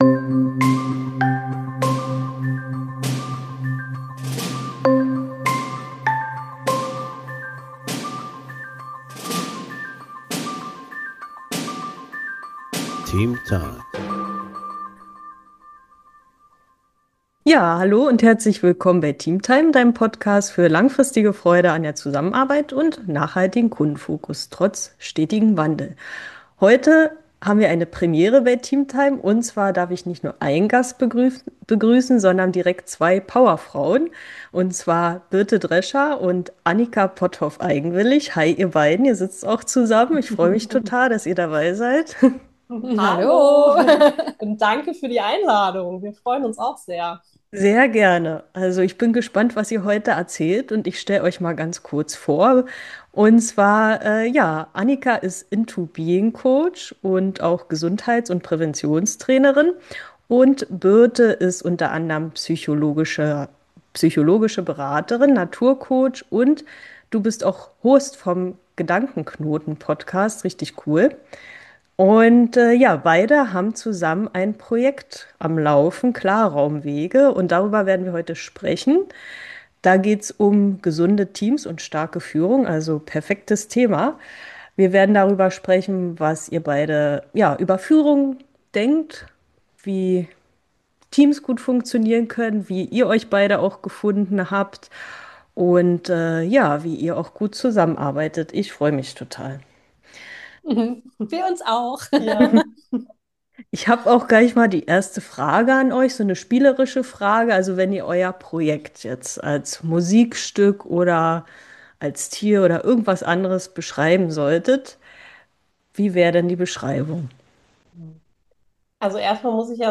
Frauenpower im Interview